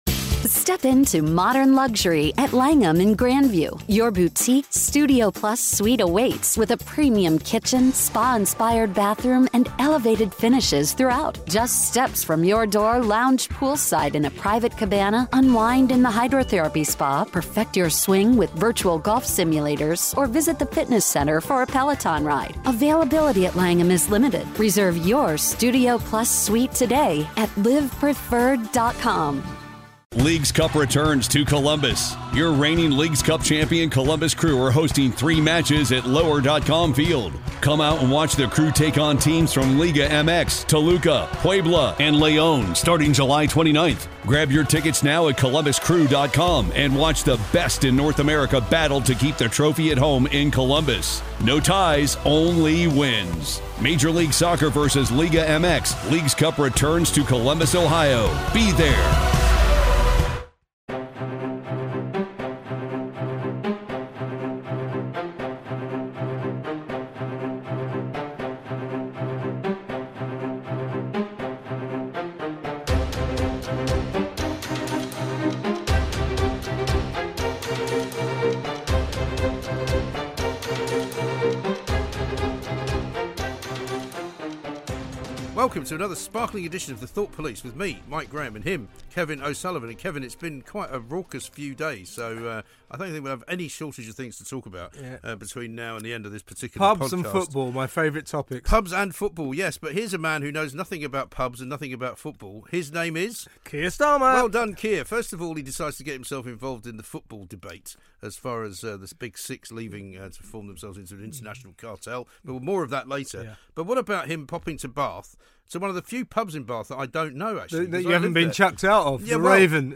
It's another angry half hour of the Thought Police.